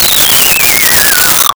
Comical Descent
Comical Descent.wav